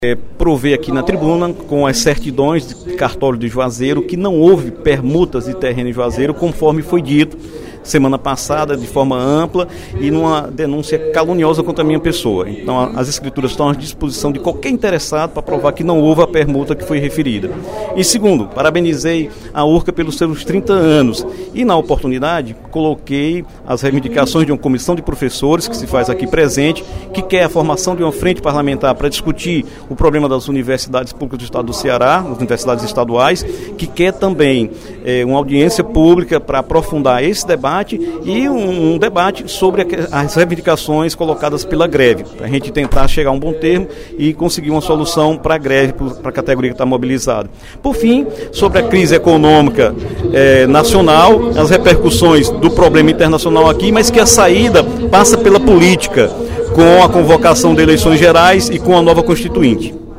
O deputado Dr. Santana (PT) apresentou, na tribuna da Assembleia Legislativa, durante o primeiro expediente da sessão plenária desta terça-feira (14/06), certidão do Cartório de Juazeiro do Norte que mostra que não foi feita nenhuma permuta de terreno no município durante sua gestão como prefeito.